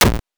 8 bits Elements
hit_7.wav